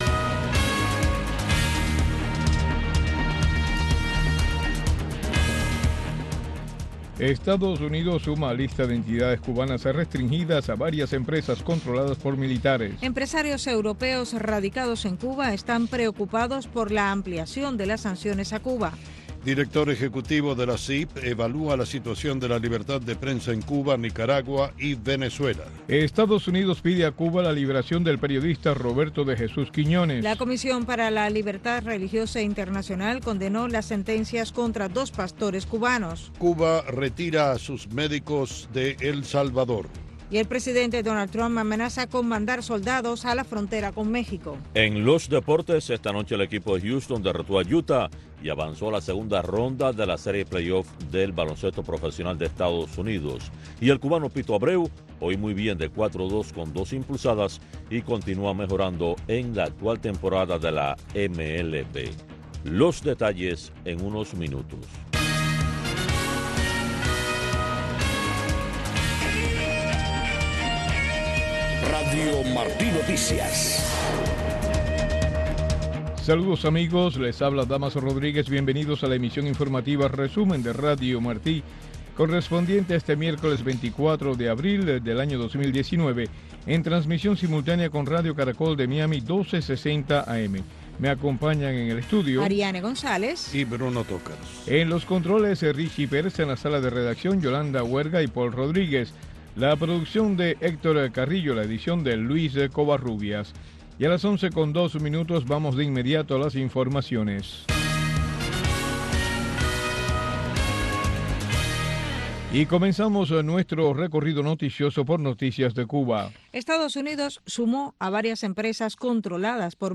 Noticiero de Radio Martí 11:00 PM